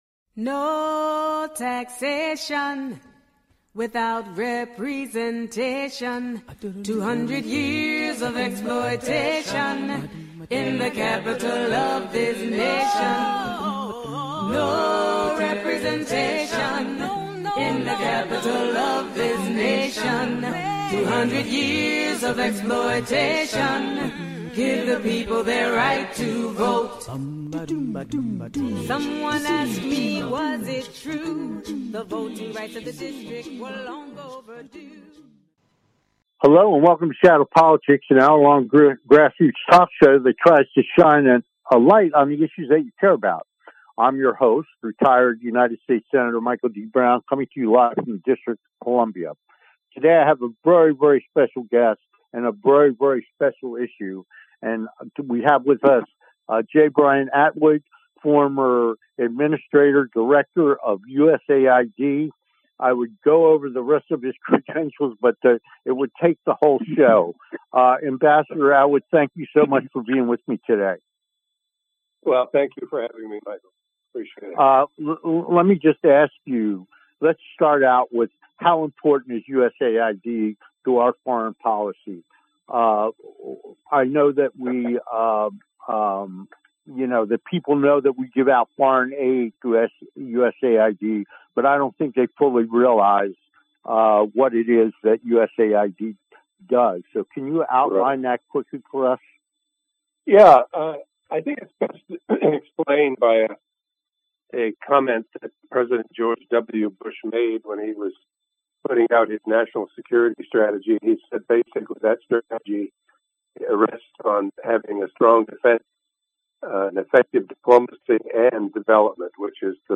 Talk Show Episode, Audio Podcast, Shadow Politics and You're Fired!
You're Fired! - With guest Former USAID Administrator J. Brian Atwood
Former USAID Administrator J. Brian Atwood will give us his take on this recent development and what this means for the agency's 10,000 employees across the globe.